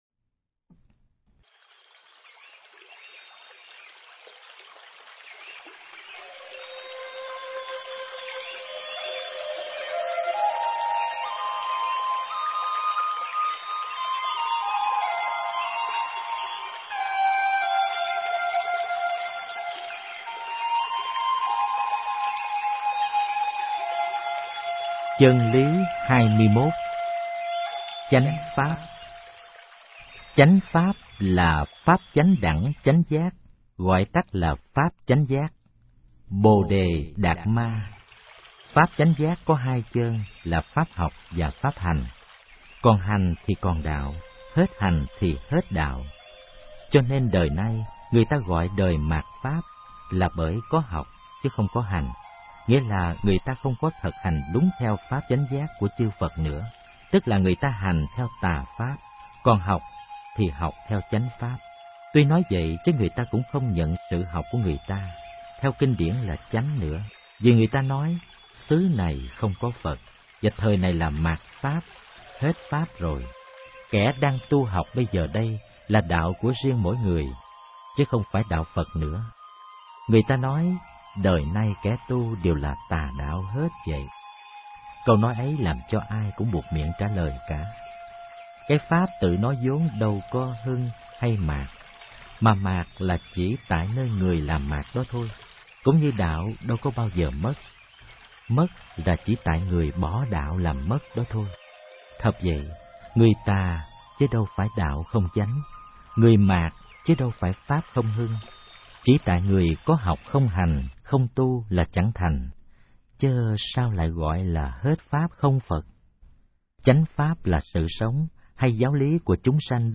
Nghe sách nói chương 21. Chánh Pháp